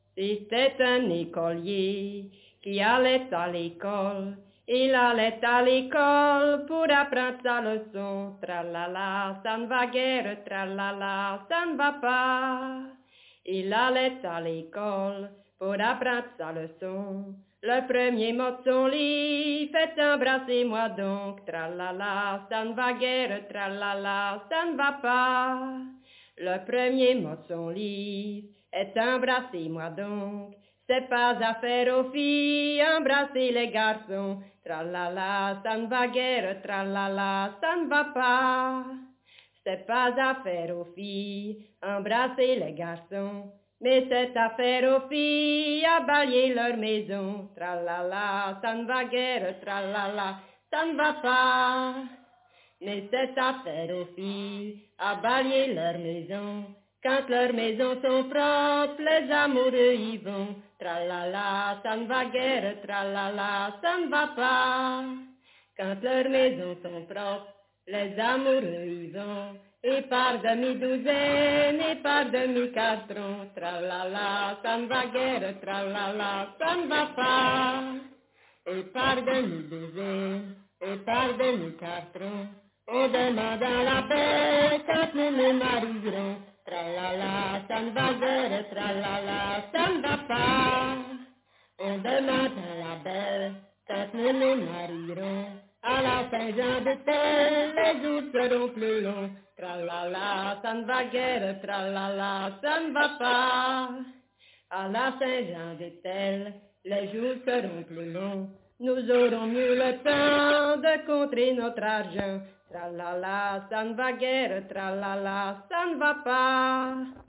Genre : chant
Type : chanson narrative ou de divertissement
Interprète(s) : Anonyme (femme)
Lieu d'enregistrement : Hastière
Support : bande magnétique
Bande endommagée.